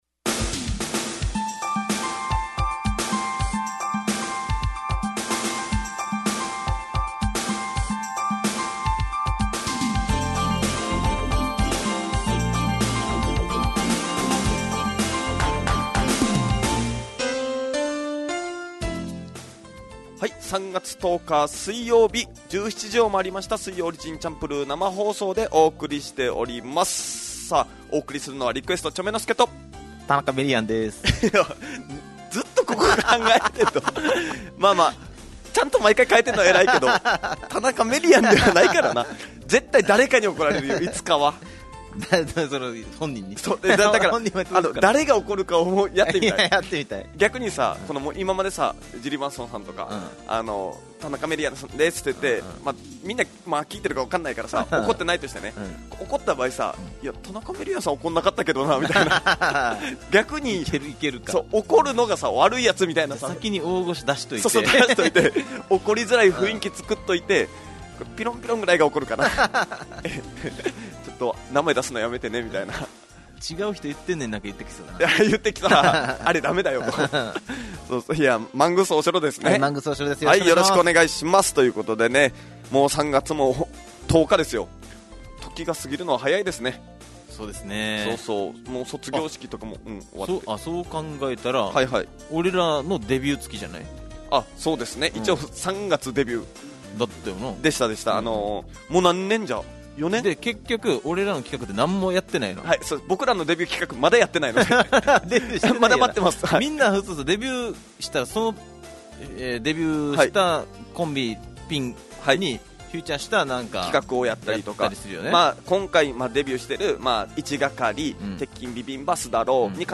fm那覇がお届けする沖縄のお笑い集団・オリジンメンバー出演のバラエティ番組